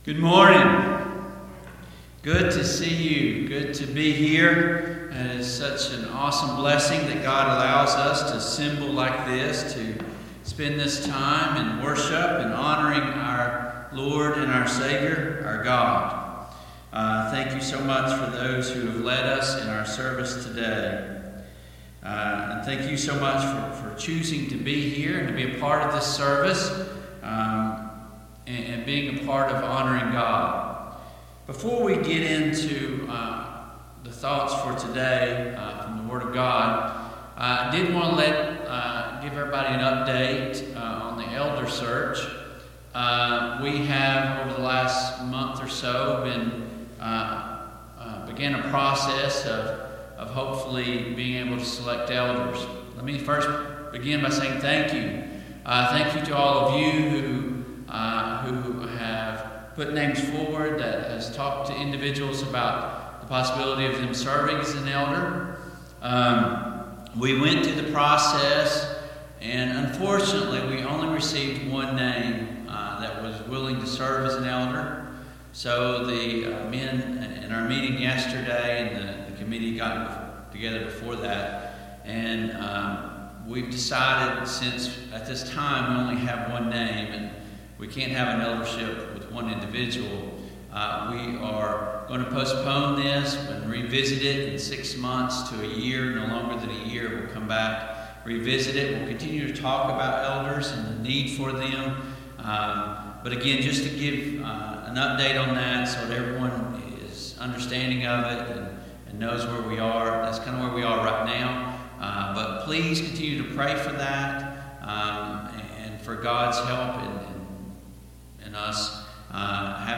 Service Type: AM Worship Topics: The Lamb of God , The Passover , The Scheme of Redemption